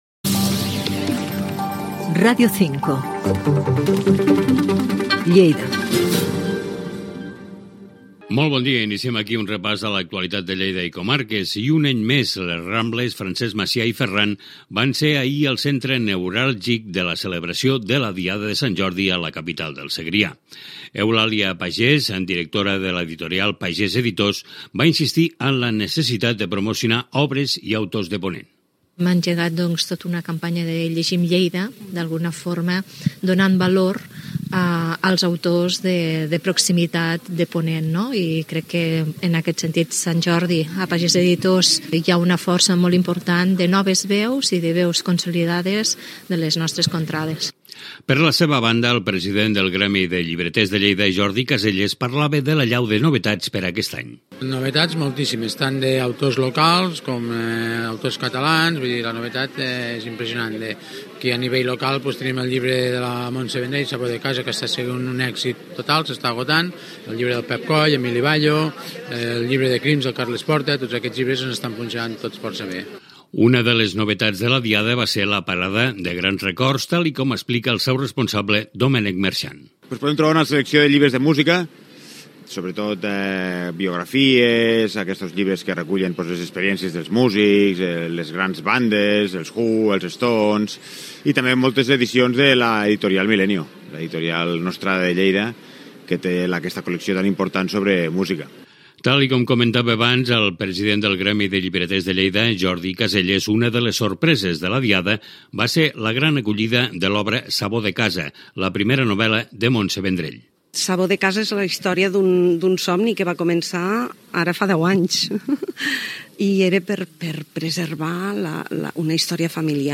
Indicatiu de l'emissora, la diada de Sant Jordi a Lleida, amb declaracions de responsables d'editorials.
Informatiu